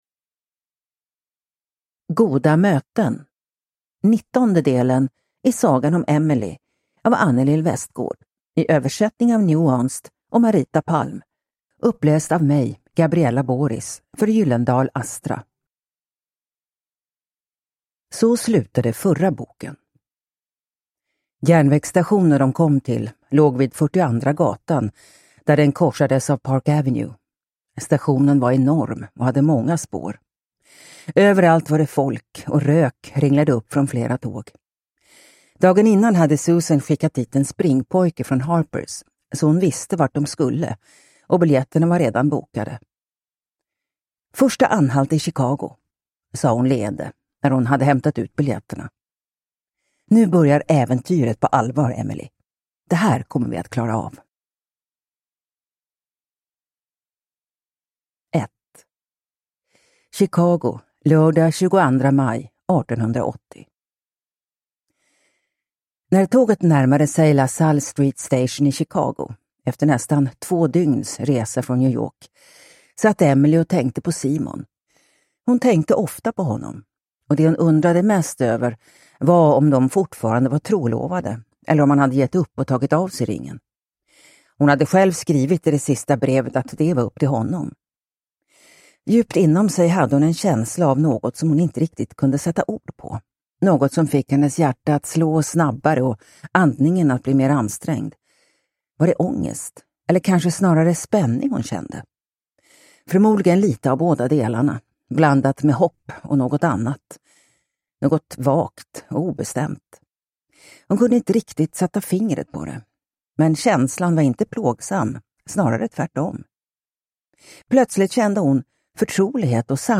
Goda möten – Ljudbok